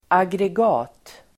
Ladda ner uttalet
Uttal: [agreg'a:t]